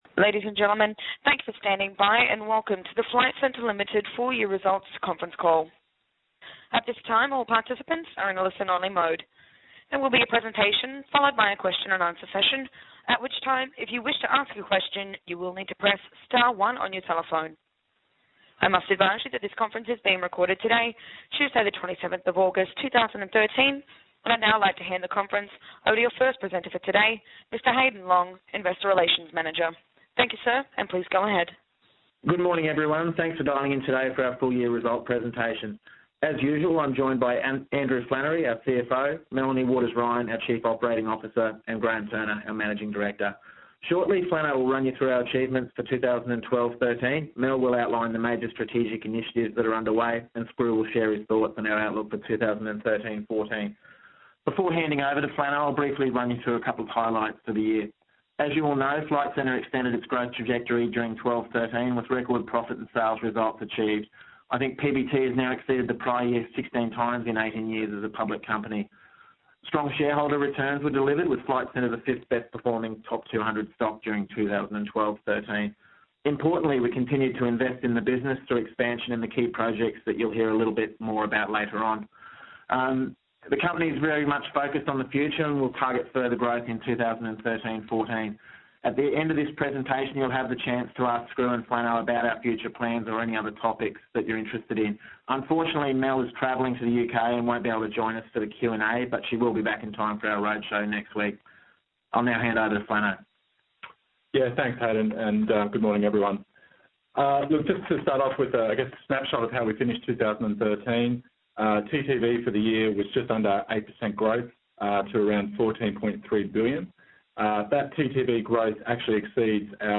FLT 2013 Full Year Result Presentation Teleconference Audio (MP3)